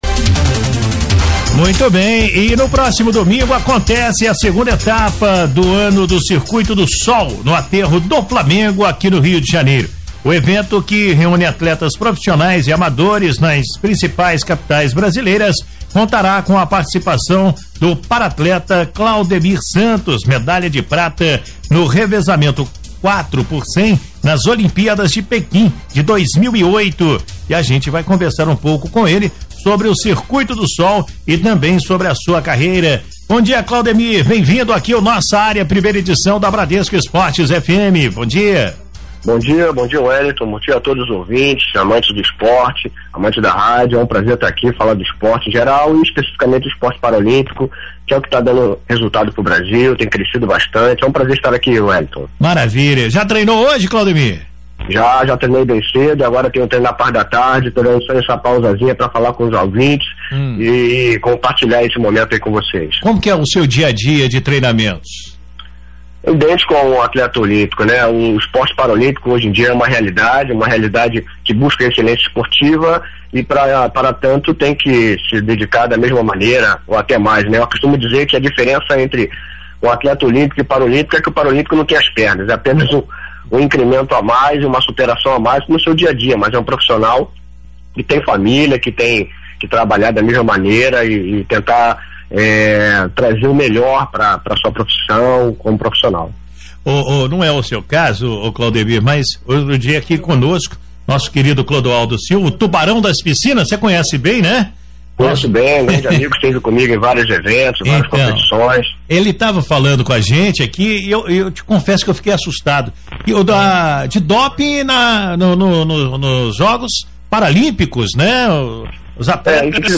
deu entrevista ao vivo para a Rádio Bradesco Esportes FM – Rio de Janeiro, nesta sexta-feira, dia 24.